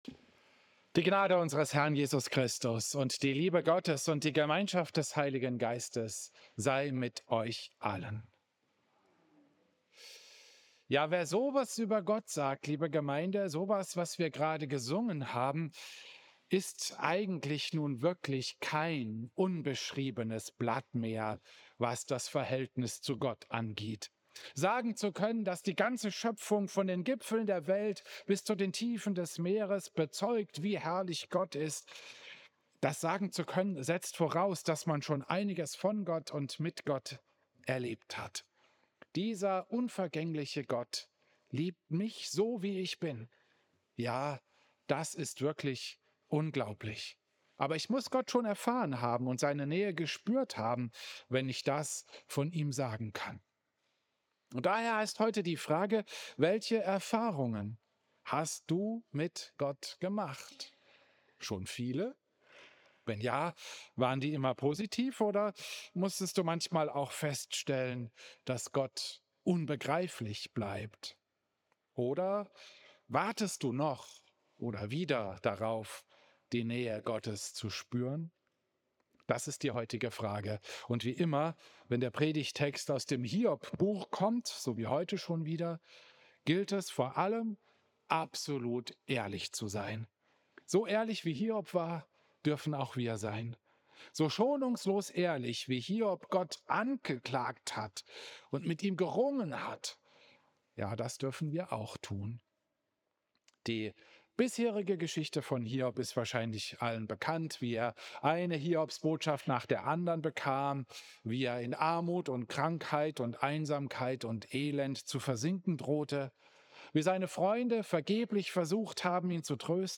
Predigt
Klosterkirche Volkenroda, 16. November 2025